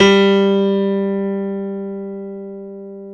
SG1 PNO  G 2.wav